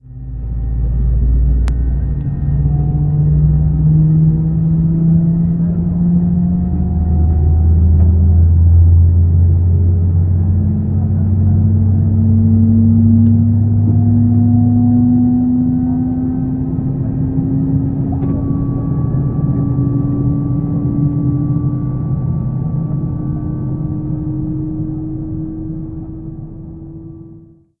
CFM56 startup sounds
cfm-startup-rear.wav